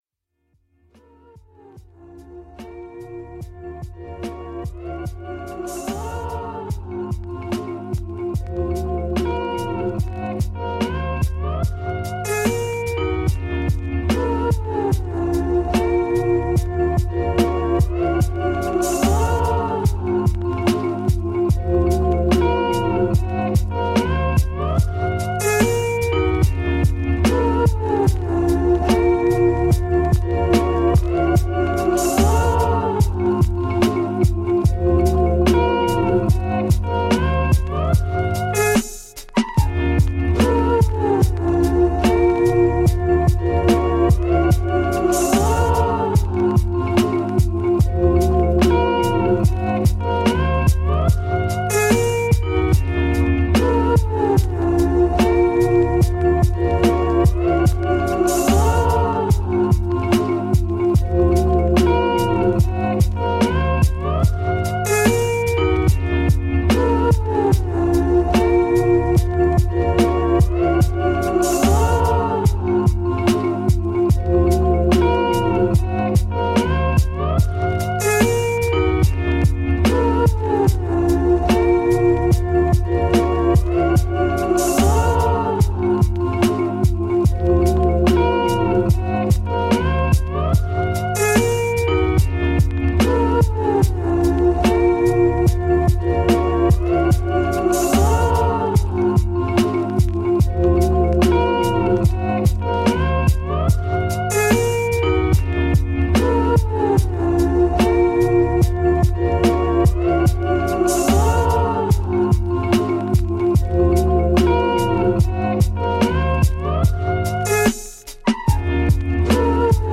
Aucun bruit parasite, aucune coupure soudaine.